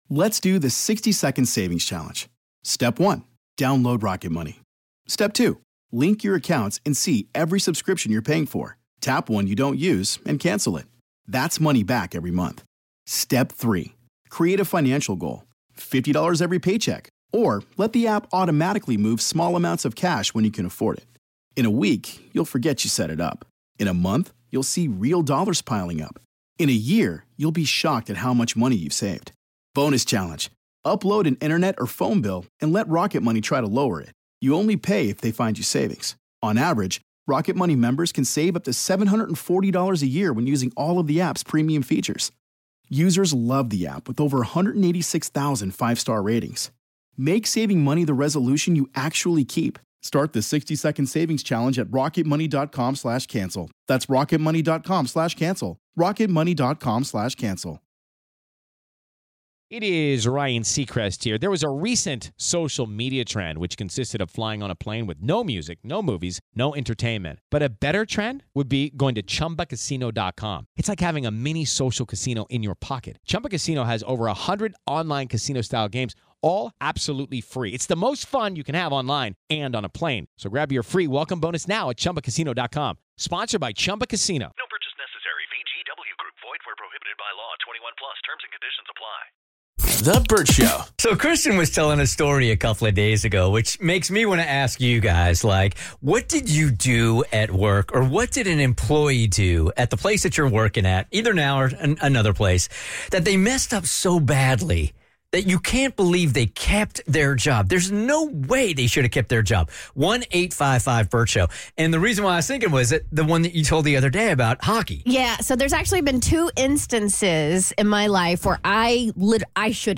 We heard from Bert Show Fam who *should* have been fired...like this woman who called in about her coworker who released the wrong man from JAIL!